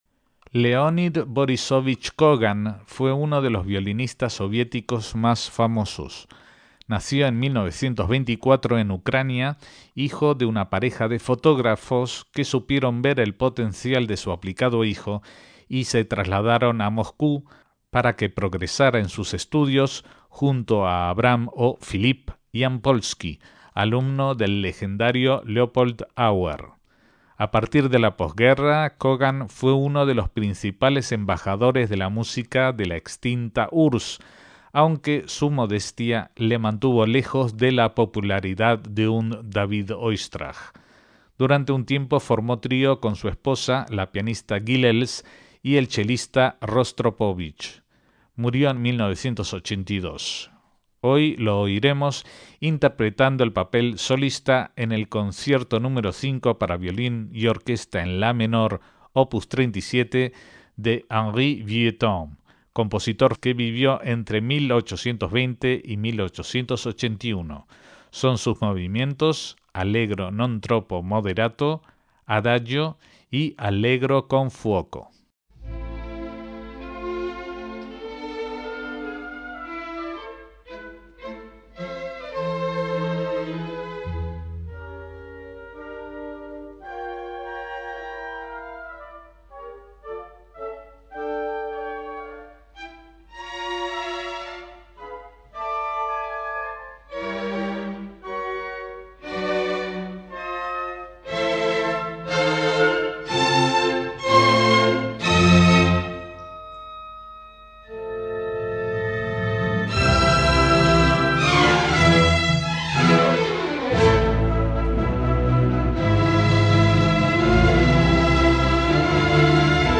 Leonid Kogan interpreta el Concierto Nº5 para violín y orquesta de Henri Vieuxtemps
MÚSICA CLÁSICA - Leonid Kogan (1924 - 1982) fue uno de los mayores virtuosos del violín del siglo XX, y uno de los artistas soviéticos más representativos, nacido en Ucrania, estudiante con Yampolsky y Thibaud.
Lo oiremos como solista del Concierto para Violín n.º 5 en La menor Op. 37 de Henri Vieuxtemps, junto a la orquesta Sinfónica Estatal de la URSS dirigida por Kirill Kondrashin.